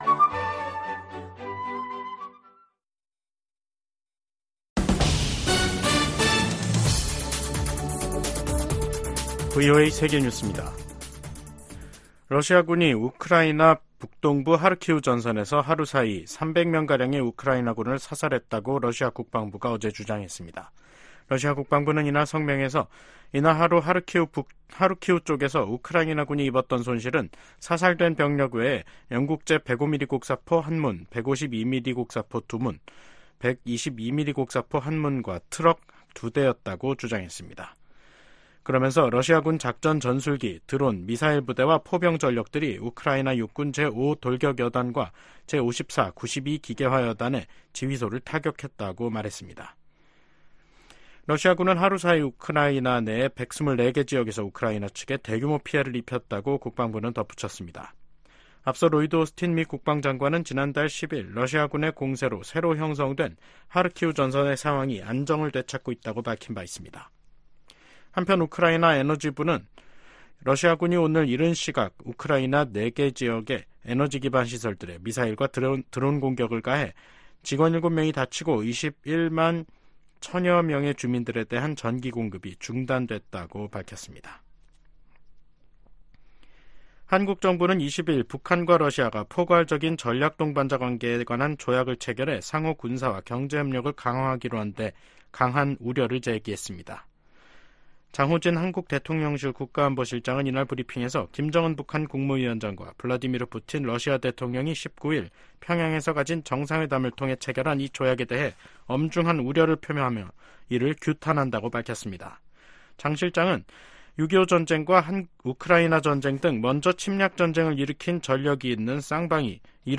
VOA 한국어 간판 뉴스 프로그램 '뉴스 투데이', 2024년 6월 20일 2부 방송입니다. 북한과 러시아가 새 조약을 통해 어느 한쪽이 무력침공을 받아 전쟁 상태에 놓이면 지체 없이 군사적 원조를 제공하기로 했습니다. 미국 정부는 이에 대해 한반도 평화와 안정, 국제 비확산 체제, 러시아의 잔인한 우크라이나 침략 전쟁 등을 지적하며 심각한 우려 입장을 나타냈습니다. 한국 정부도 국제사회의 책임과 규범을 저버리는 행위라며 이를 규탄한다고 밝혔습니다.